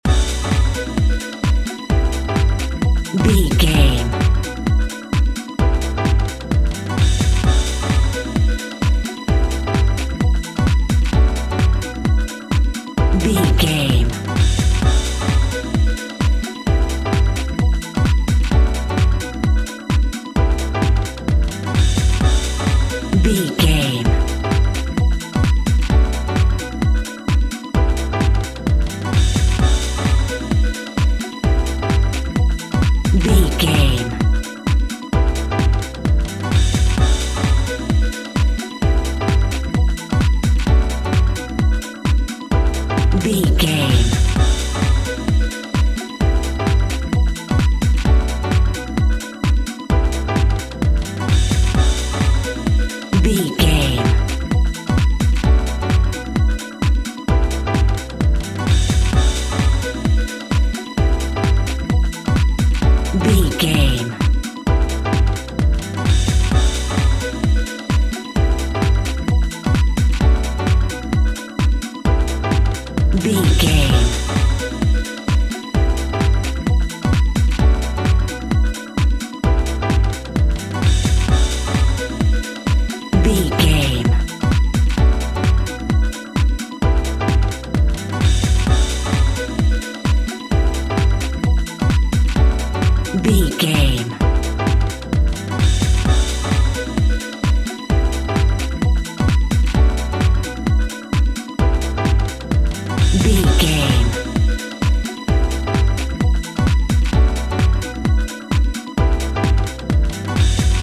Aeolian/Minor
groovy
uplifting
futuristic
driving
energetic
electric piano
bass guitar
synthesiser
drums
funky house
disco funk
synth lead
synth bass
synth drums